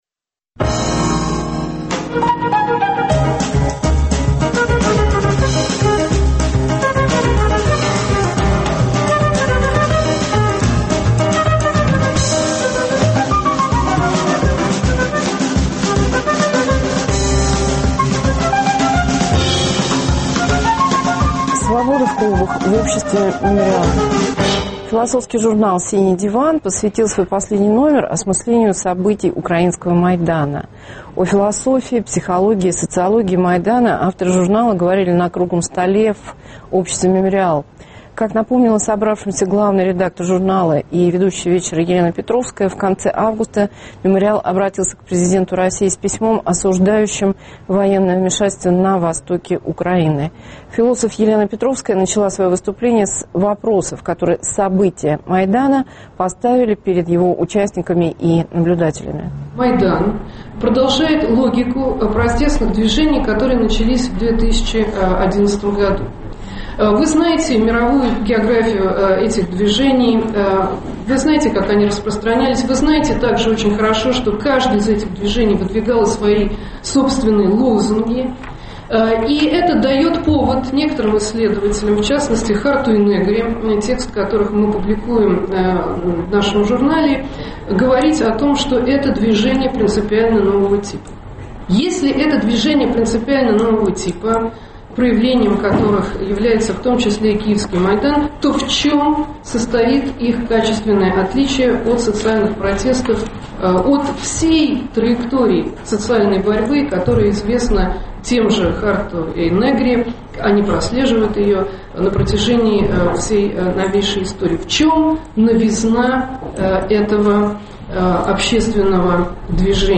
О философии, психологии, социологии Майдана авторы журнала говорят на круглом столе в обществе "Мемориал".